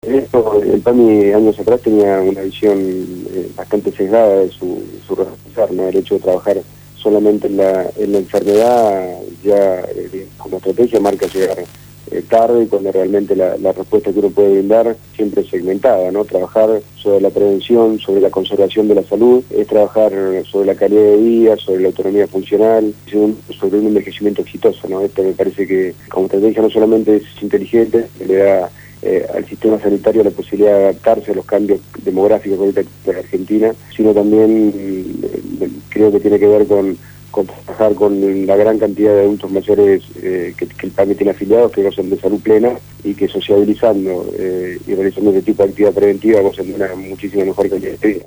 Federico Susbielles Gerente de Promoción Social y Comunitaria de la obra social de los jubilados, PAMI, fue entrevistado en el programa «Punto de partida» (Lunes a viernes de 7 a 9 de la mañana) por Radio Gráfica; en donde dio detalles del reciente convenio firmado con la secretaría de niñez, adolescencia y familia.